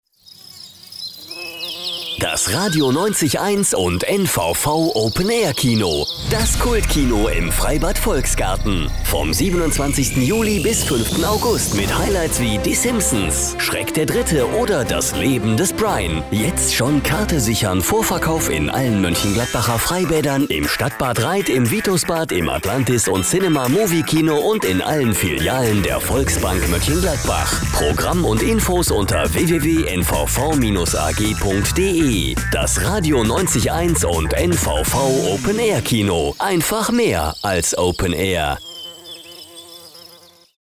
deutscher Sprecher.
Sprechprobe: Werbung (Muttersprache):
german voice over artist